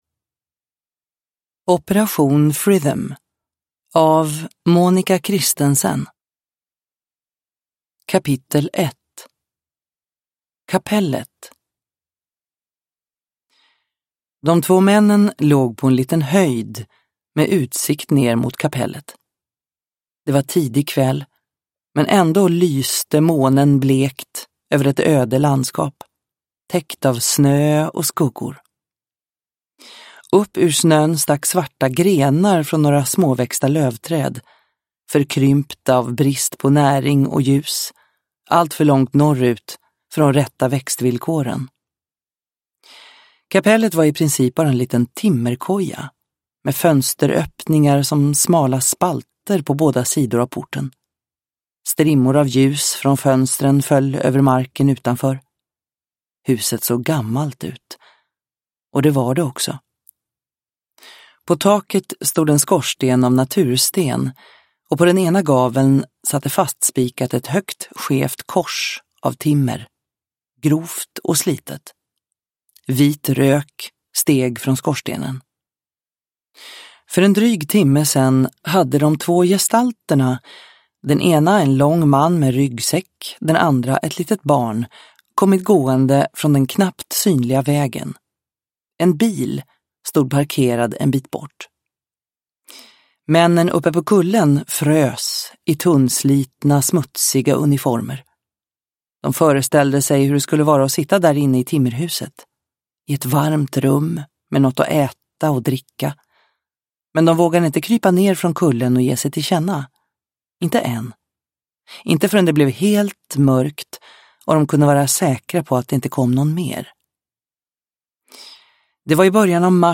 Operation Fritham – Ljudbok – Laddas ner